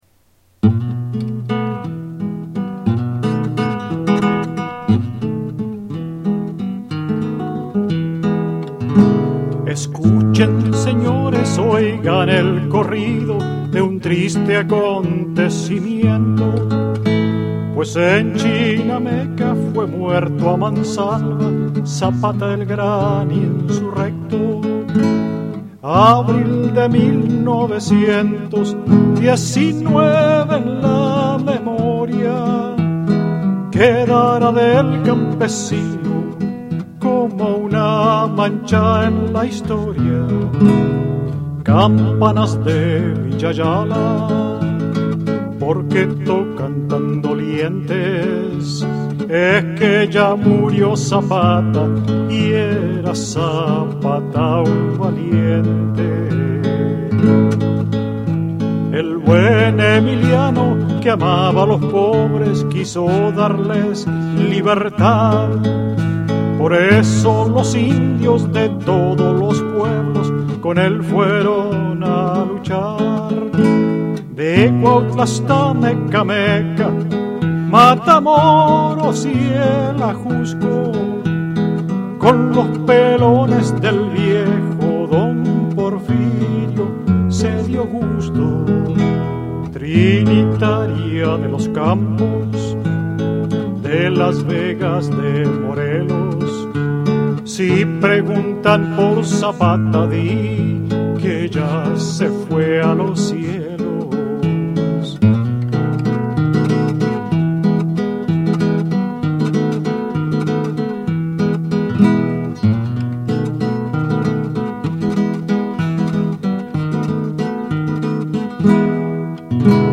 Mexico in the Heart on the Presentation of "No Word for Welcome" in Boston